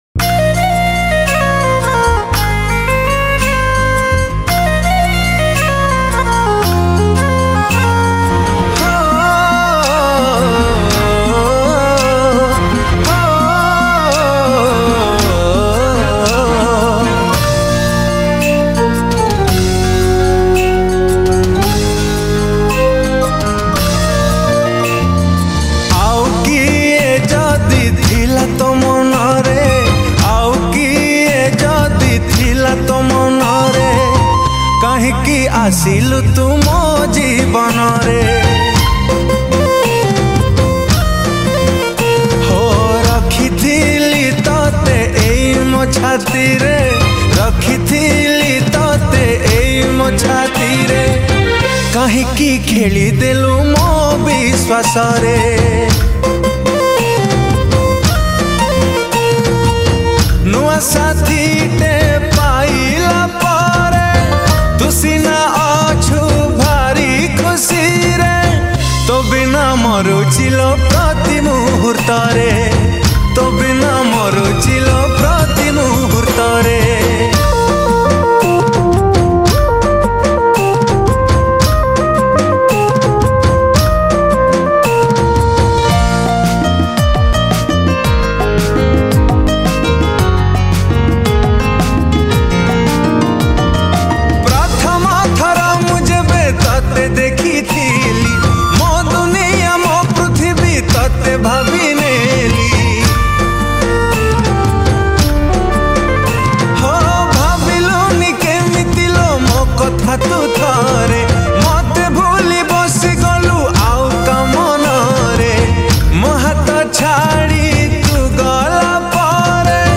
Sad Romantic Songs